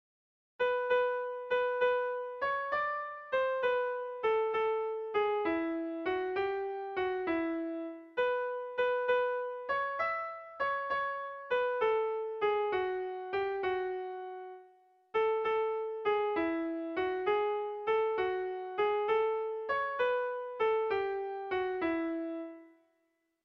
Air de bertsos - Voir fiche   Pour savoir plus sur cette section
Irrizkoa
ABDBE